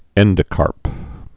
(ĕndə-kärp)